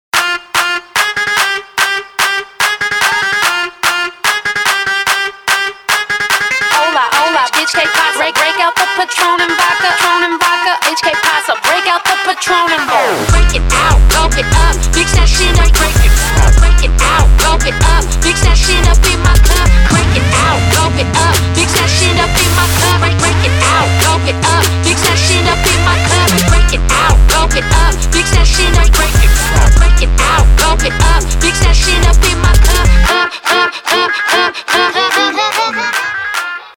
• Качество: 320, Stereo
Трэп и Хип-хоп в женском исполнении!